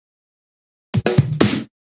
Fill 128 BPM (39).wav